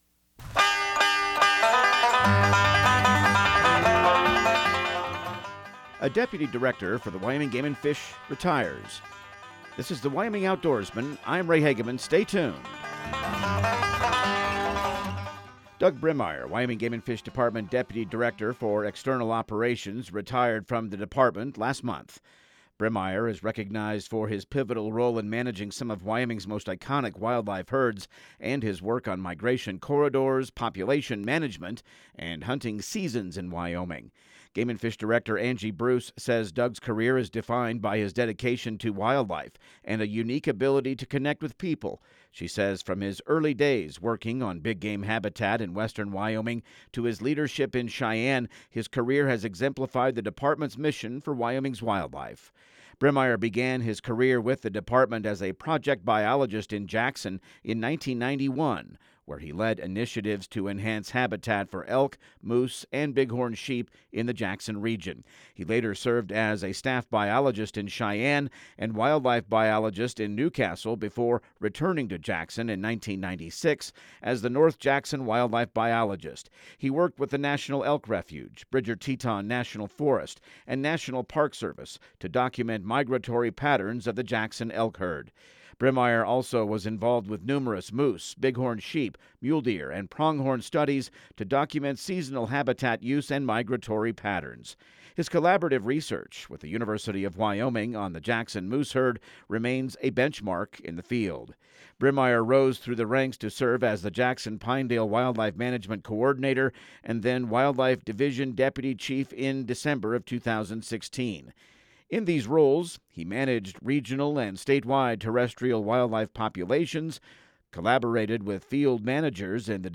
Radio news | Week of February 16